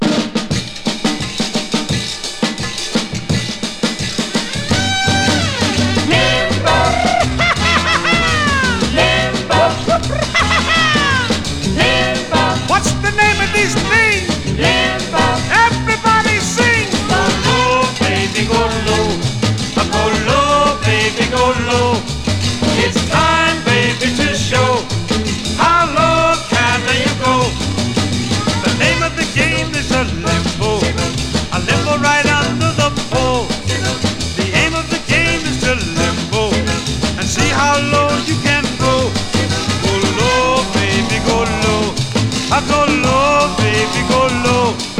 Pop, Vocal, Limbo　USA　12inchレコード　33rpm　Mono